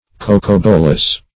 cocobolas - definition of cocobolas - synonyms, pronunciation, spelling from Free Dictionary
Search Result for " cocobolas" : The Collaborative International Dictionary of English v.0.48: Cocobolo \Co`co*bo"lo\, Cocobolas \Co`co*bo"las\, n. [Sp. cocobolo.]